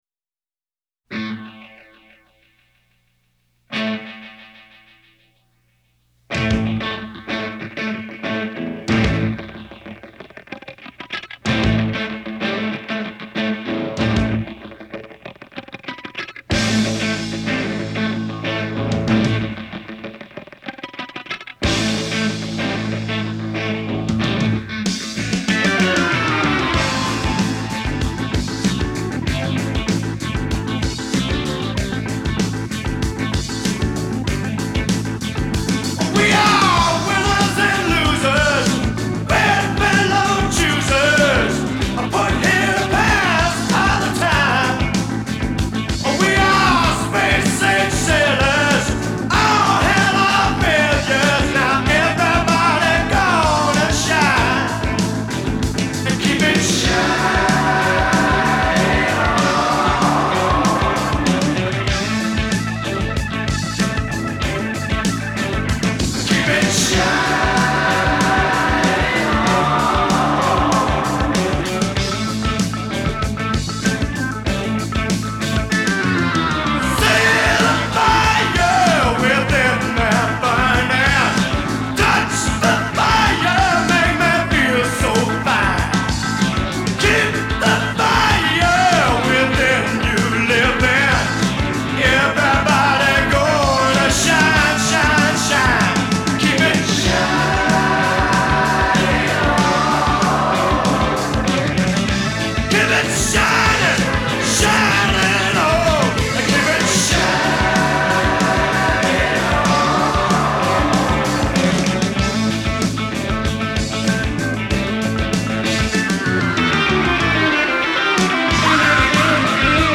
американская хард-рок-группа